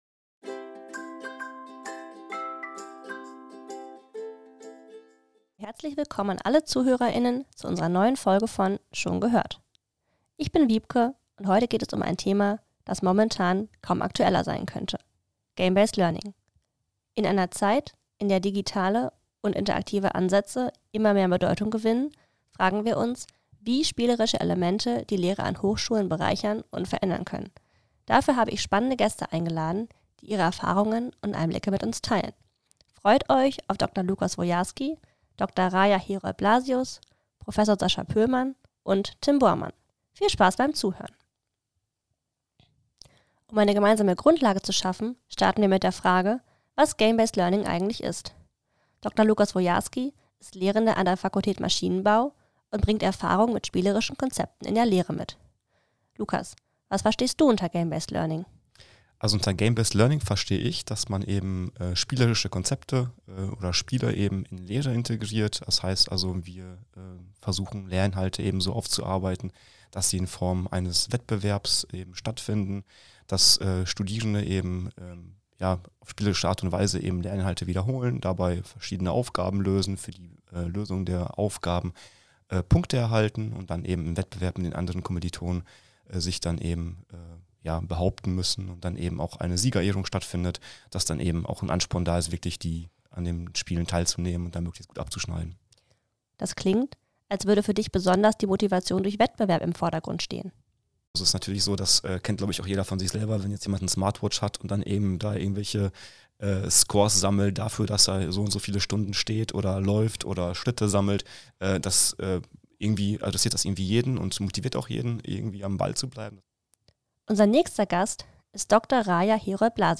In der siebten Podcast-Folge von „Schon gehört?“ geht es um Game-based Learning in der Hochschullehre. Unsere studentischen Mitarbeiterinnen sprechen mit drei Lehrenden der TU Dortmund über den Einsatz von Spielen in der Lehre. Außerdem mit dabei: ein Studierender, der als Studienleistung einen Chatbot mit immersiven Elementen entwickelt hat.